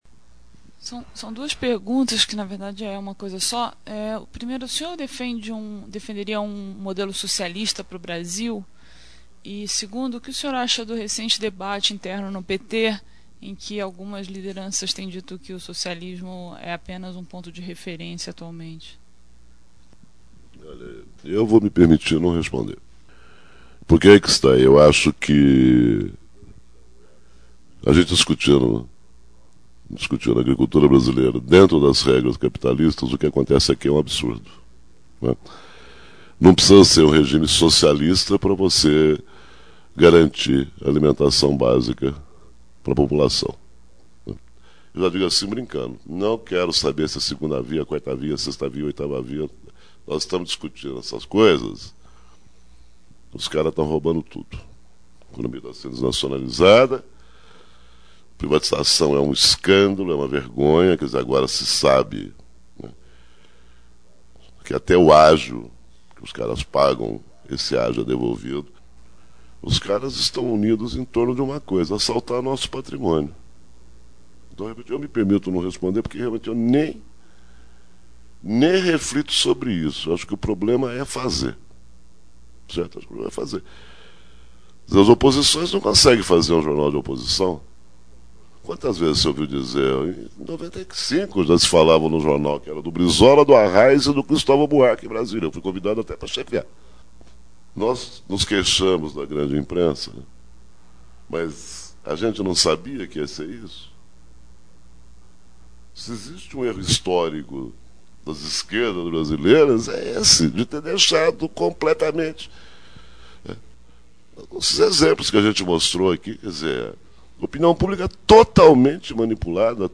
Neste trecho da palestra, Aloysio Biondi responde uma estudante de jornalismo que pergunta se ele defende um “modelo socialista” para o Brasil.
O erro das esquerdas Repórter do Futuro, na Oboré Gravado em São Paulo, 27/11/1999 Neste trecho da palestra, Aloysio Biondi responde uma estudante de jornalismo que pergunta se ele defende um “modelo socialista” para o Brasil.